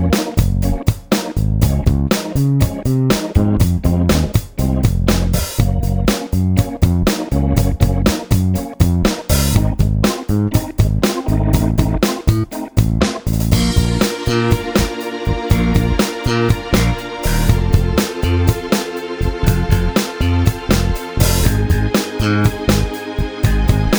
no guitars or Backing Vocals Pop (1980s) 4:19 Buy £1.50